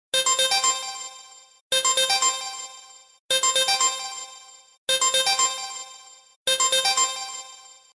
• Качество: 128, Stereo
короткие
Прикольное стандартное смс от HTC One